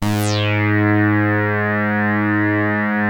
44-SAWRESWET.wav